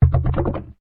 Corrosion3.ogg